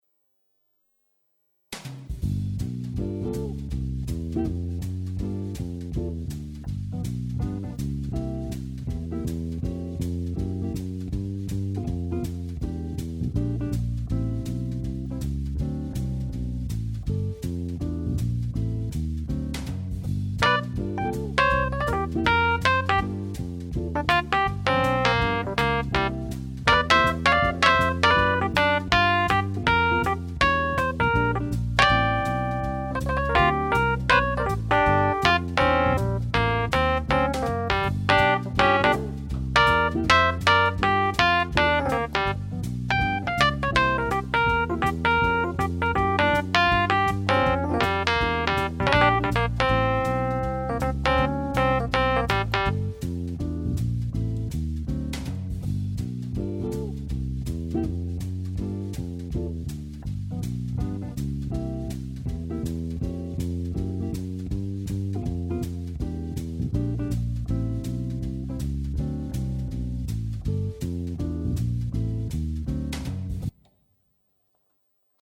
Variationen zu Bluesschema (jazzig?)
Findet da jemand heraus, welche Akkordfolgen das sind in G? Ich improvisiere da in der G-Moll Bluestonleiter drauf, bin aber gar nicht sicher, dass das überhaupt Moll ist... eher eigentlich Dur, aber wie oft im Blues geht Beides ja dank der Bluenotes teilweise ineinander über.
Klar, es geht mit G7 los, dann C7, dann G7, aber dann weiter?